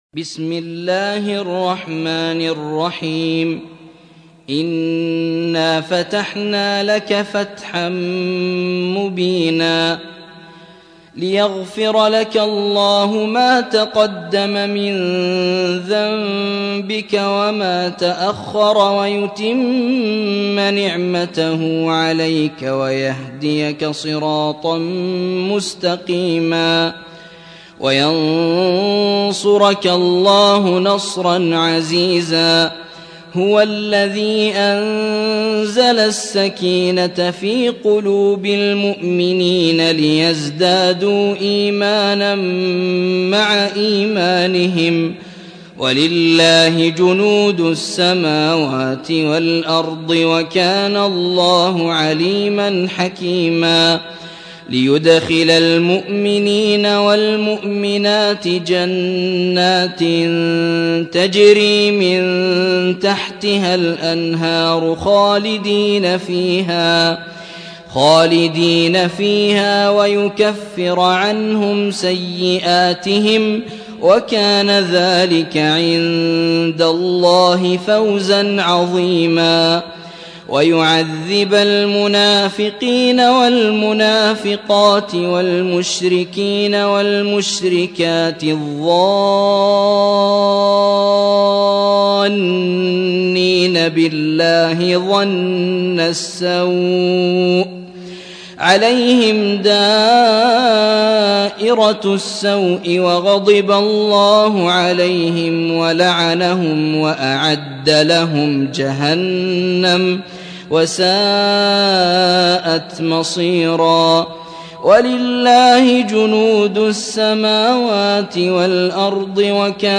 تحميل : 48. سورة الفتح / القارئ يوسف الشويعي / القرآن الكريم / موقع يا حسين